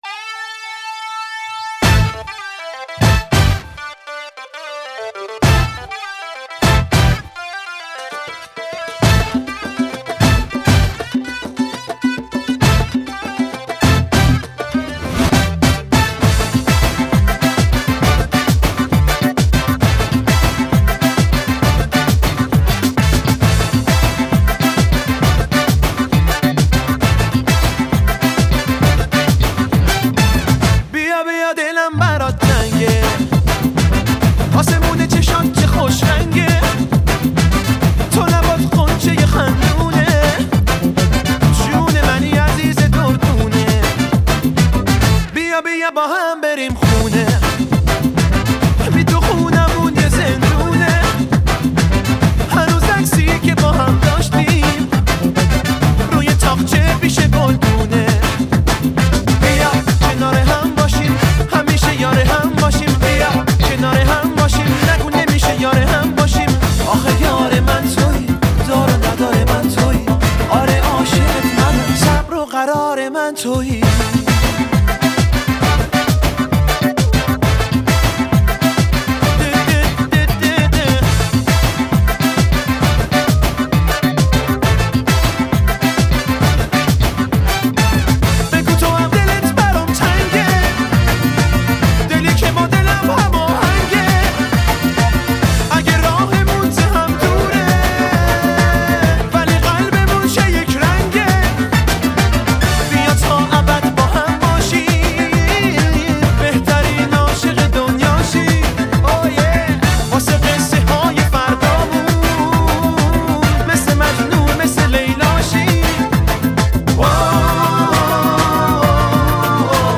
زیبا و احساسی